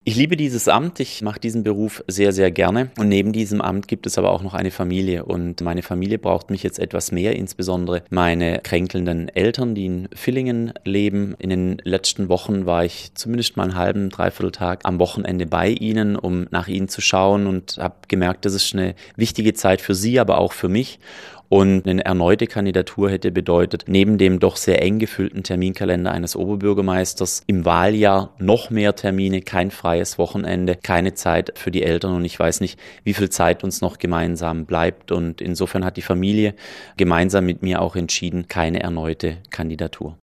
Bei einem Pressegespräch am Donnerstag sprach Steffens von der schwierigsten Entscheidung, die er in seinem Berufsleben je habe treffen müssen.
Marco Steffens, Oberbürgermiester von Offenburg